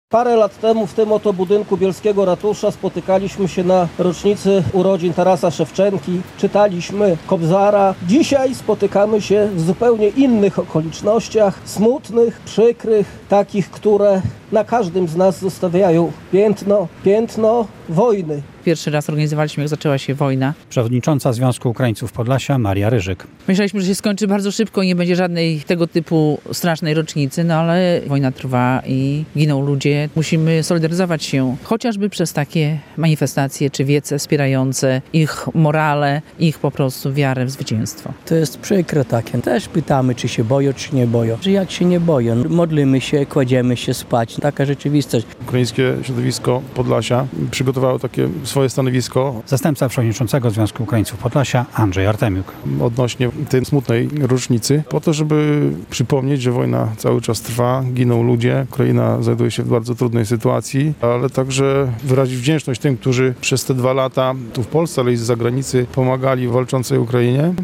Manifestację pod bielskim ratuszem zorganizował Związek Ukraińców Podlasia.
O potrzebie solidarności z walczącym sąsiadem mówił między innymi burmistrz miasta Jarosław Borowski.